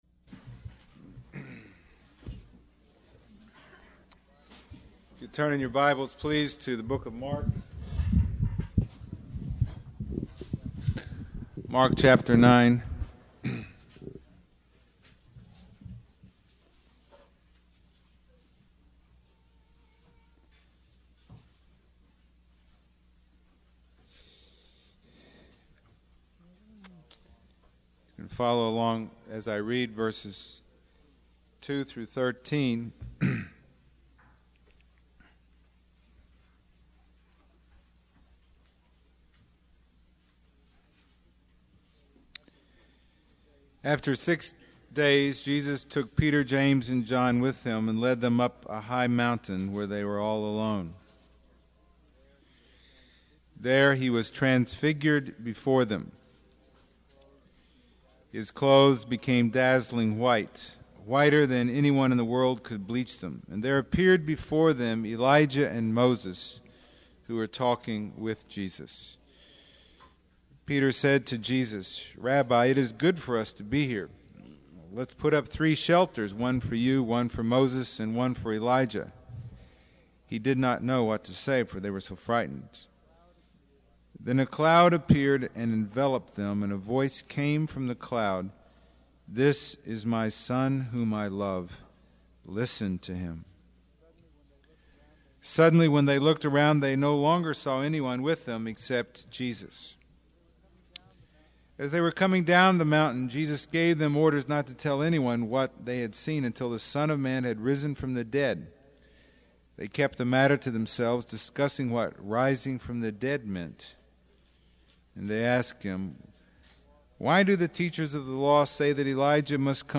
Sermon 14 | The Bronx Household of Faith